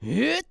CK蓄力04.wav
CK蓄力04.wav 0:00.00 0:00.51 CK蓄力04.wav WAV · 44 KB · 單聲道 (1ch) 下载文件 本站所有音效均采用 CC0 授权 ，可免费用于商业与个人项目，无需署名。
人声采集素材/男2刺客型/CK蓄力04.wav